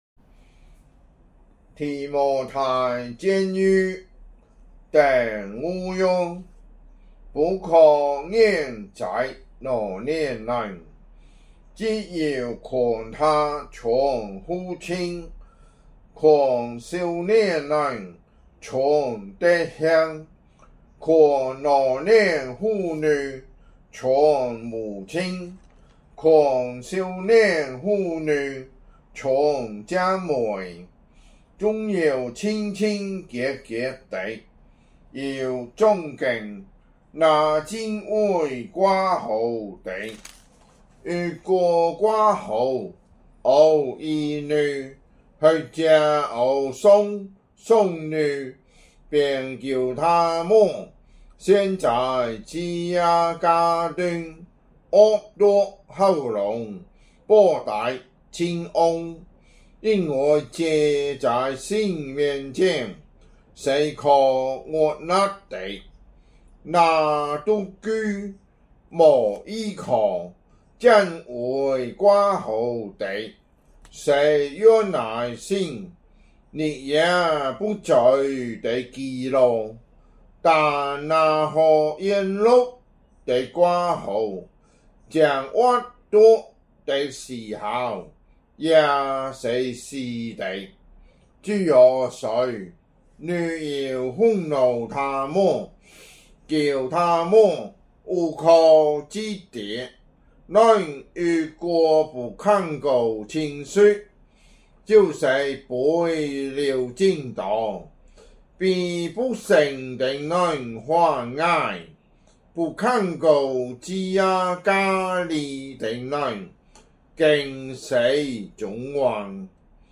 福州話有聲聖經 提摩太前書 5章